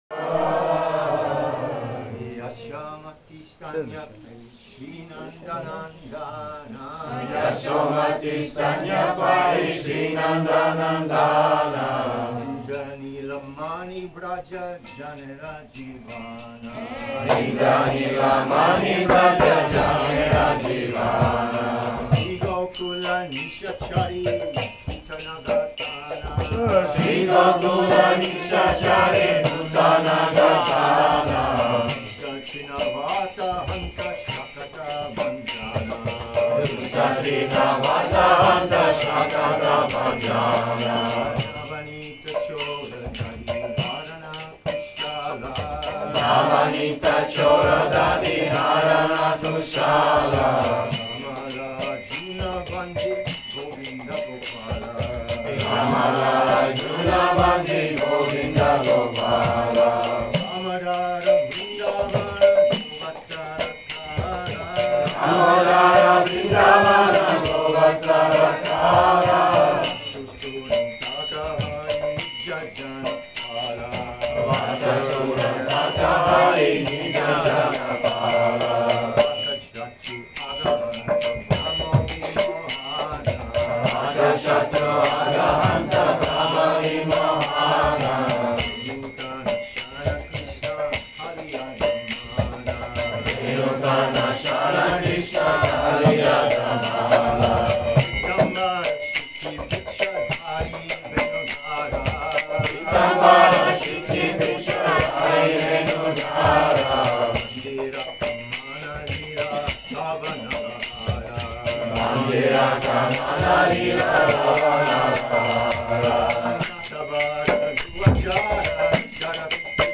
Govinda Farm, Thailand | «Шри Кришнера вимсоттара-шата-нама».
Ферма Говинда, Таиланд